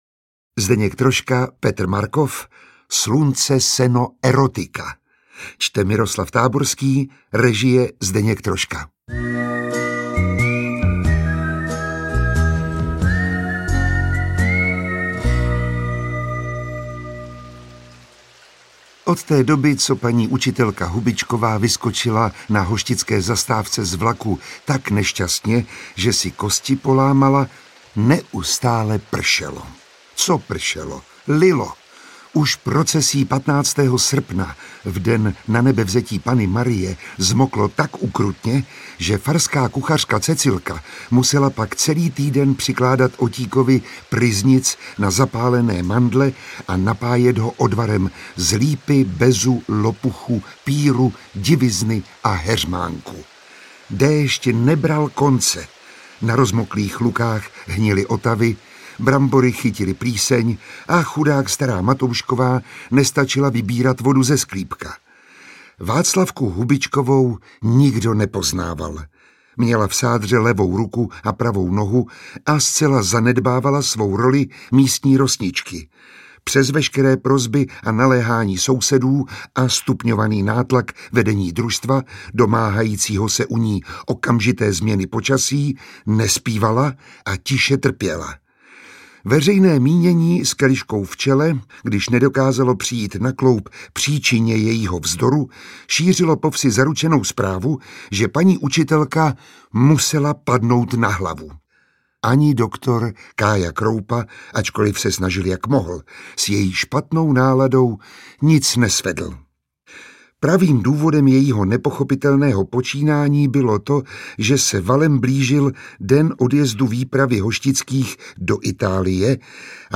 Interpret:  Miroslav Táborský
Literární zpracování legendárních filmových komedií v audioknižní podobě. Čte Miroslav Táborský, režie Zdeněk Troška. Svérázní občané jihočeské vesničky Hoštice se vypraví do Itálie.